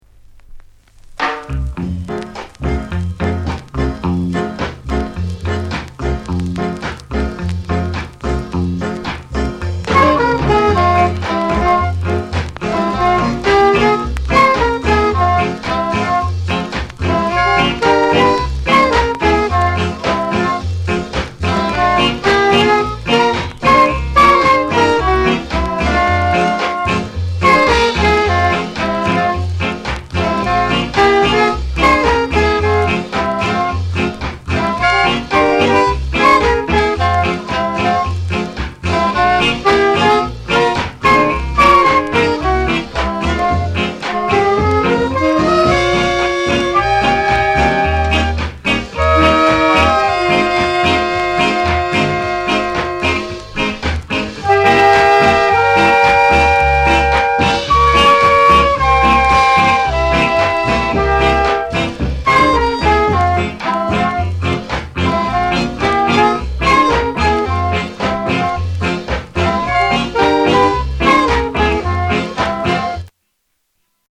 SOUND CONDITION A SIDE VG(OK)